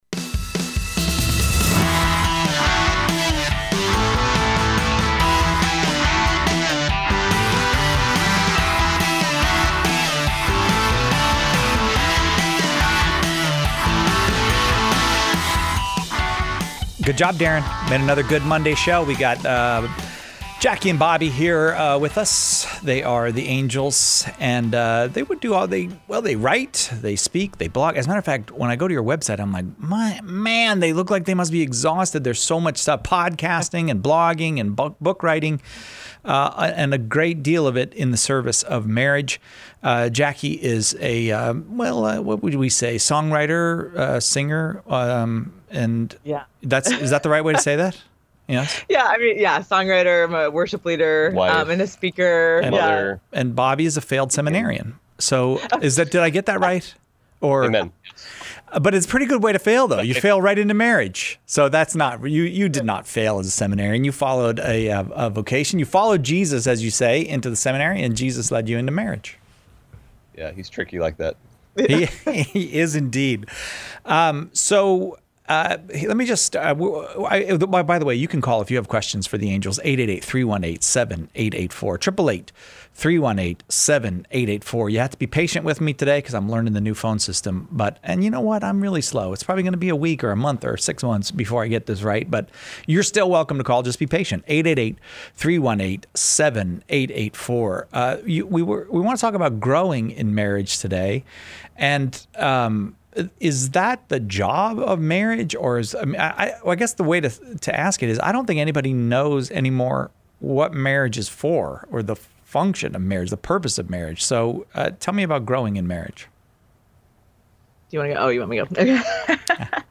give counsel to callers on how to grow in holiness in marriage.